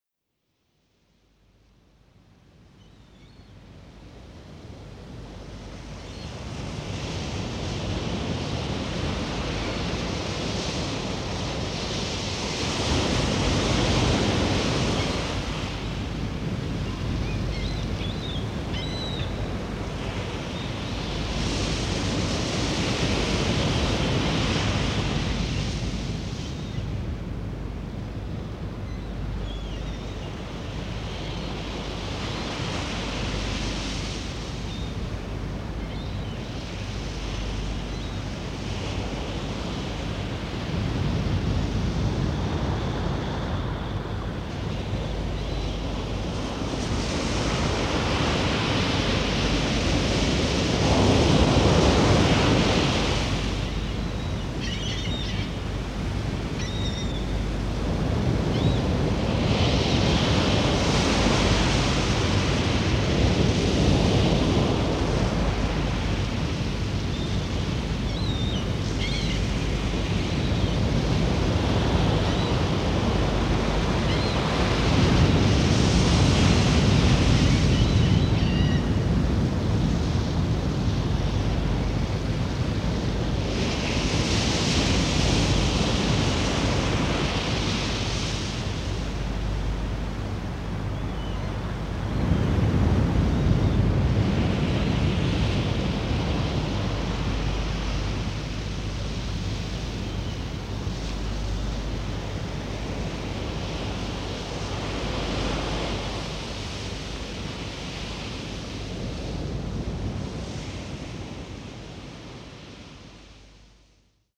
Wonderfully relaxing Hemi-Sync® sound patterns support the richly detailed verbal guidance of The Visit to transport you beyond time, into the presence of those who love and understand you.
A Hemi-Sync classic, voiced by Robert Monroe. 44 min.